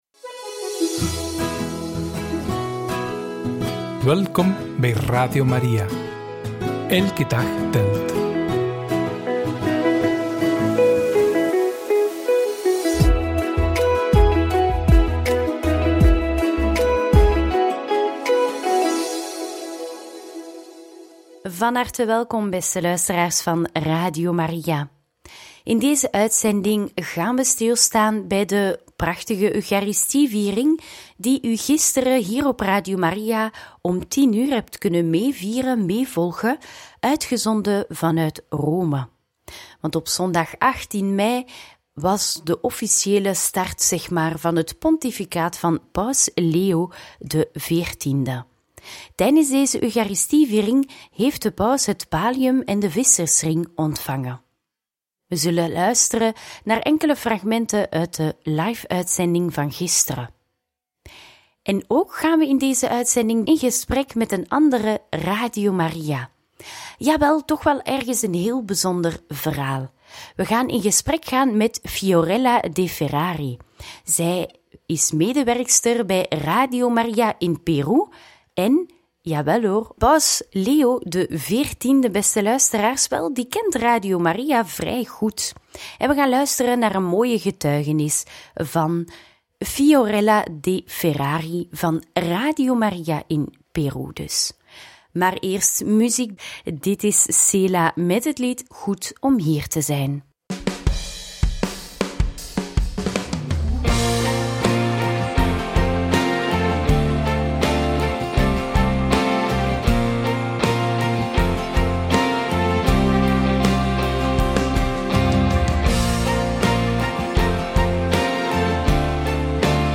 Homilie van Paus Leo XIV op zondag 18 mei 2025 en Radio Maria Perú vertelt over Mgr. Prevost!